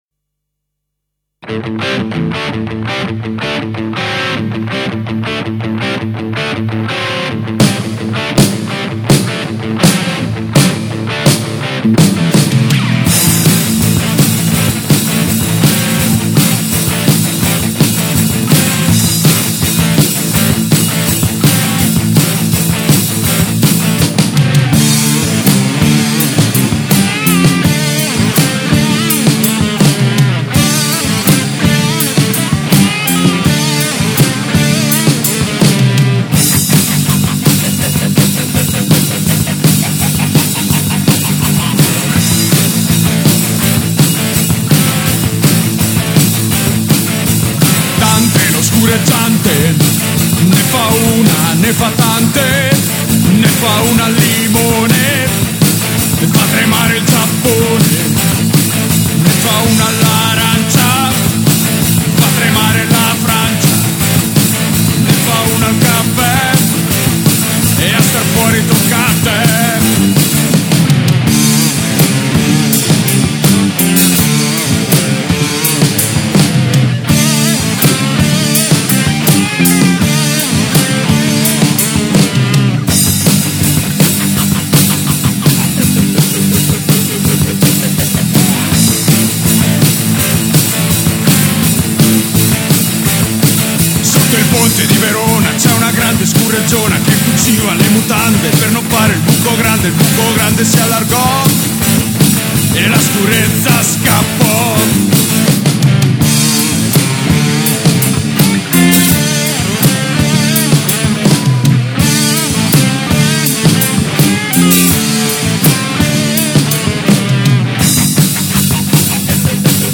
Musica Demenziale e altro...